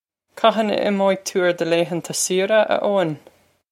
Pronunciation for how to say
Kah-hin ah im-oh-ig too air duh lay-han-ta see-ra, ah Oh-in?
This is an approximate phonetic pronunciation of the phrase.